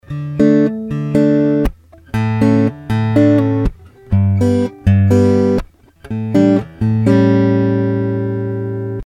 3) Через усилитель Genz-Benz Shen Pro (с прямым выходом из усилителя) с использованием предусилителя FET
Гитара, K4 и BenzBenz были настроены на "плоскую", без каких-либо эффектов.
И, да, Genz-Benz явно перегружал рекордер.
В двух других (3) и (4), казалось, был некоторый перегруз.